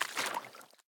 swim4.ogg